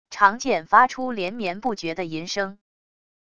长剑发出连绵不绝的吟声wav音频